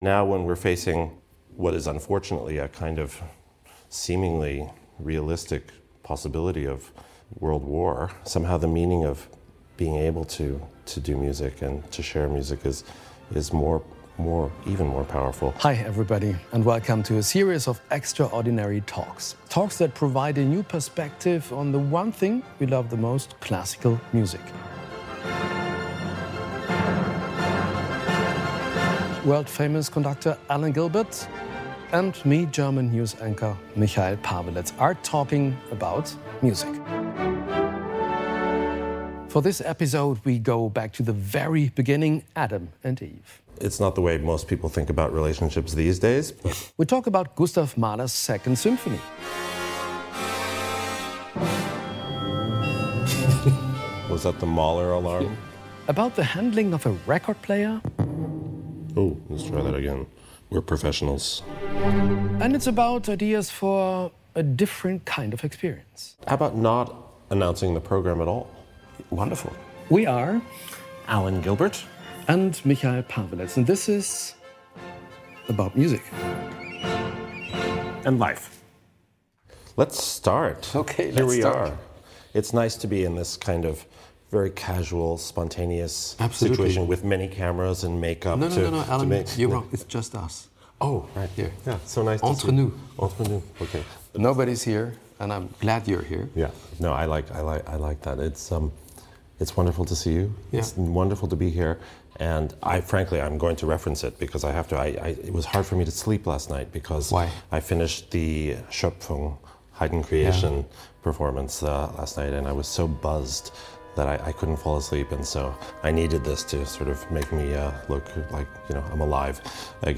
Im "about music"-Podcast des NDR sprechen Tagesschau-Moderator Michail Paweletz und Alan Gilbert, Chefdirigent des NDR Elbphilharmonie Orchesters, über ihre Faszination für klassische Musik, diskutieren wichtige gesellschaftliche Fragen und tauschen sich darüber aus, was das alles mit ihnen persönlich zu tun hat.